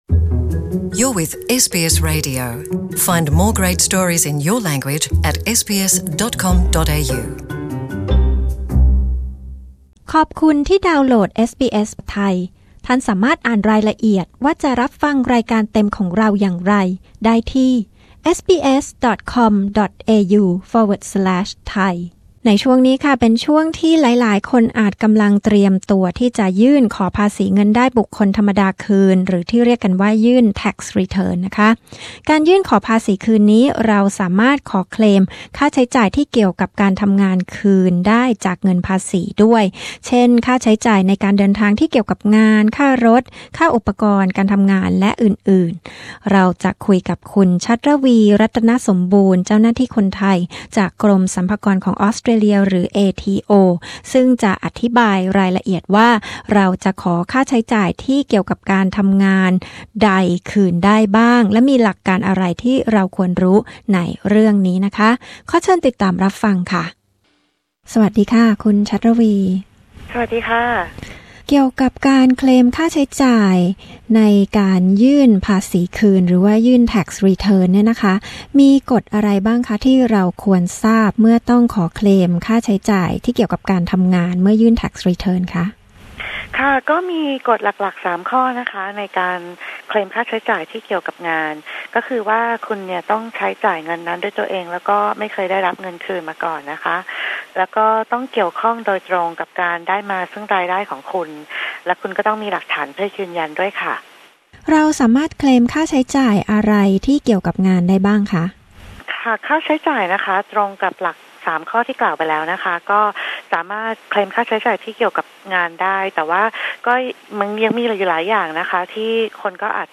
กดปุ่ม (▶) ด้านบนเพื่อฟังสัมภาษณ์เจ้าหน้าที่คนไทยของเอทีโอ